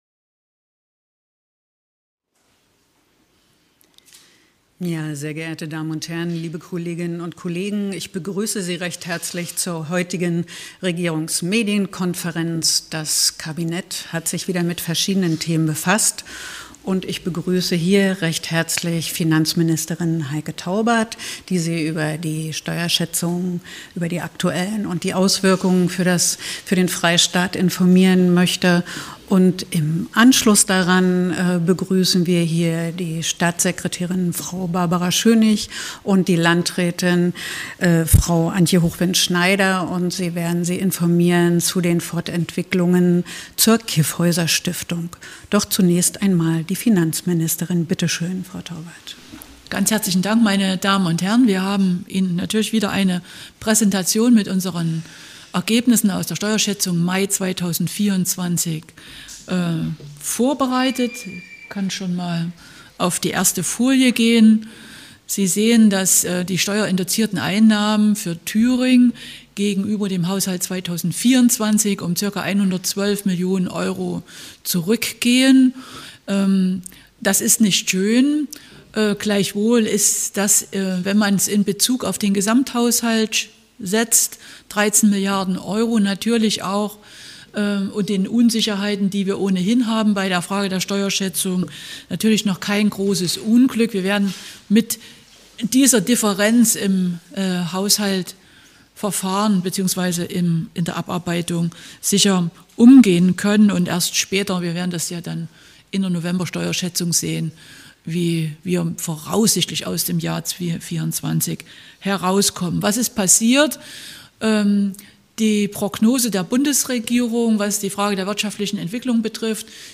Staatssekretärin Prof. Barbara Schönig und die Landrätin des Kyffhäuserkreises Antje Hochwind-Schneider haben zudem in der Regierungsmedienkonfernez in Erfurt über die Entwicklung der Kyffhäuser-Stiftung berichtet.